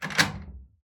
DoorLock.wav